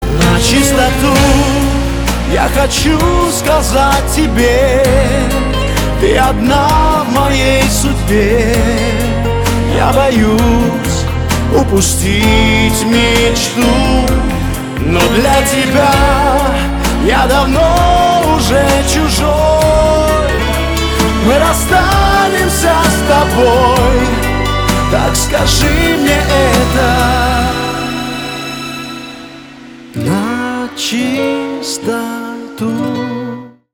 поп
мужской вокал
спокойные
романтичные